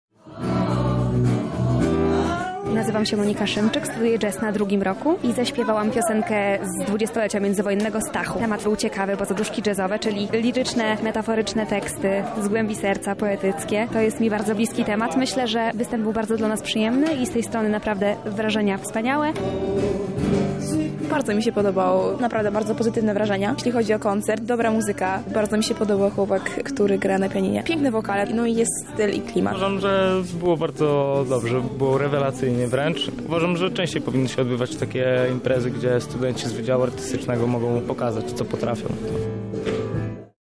Liryczność, spokój i muzyka.
Zaprezentowali oni swoje umiejętności wokalne na koncercie.
Soliści wybrali utwory spokojne i poetyckie.
Koncert odbył się w Inkubatorze Medialnym Chatki Żaka.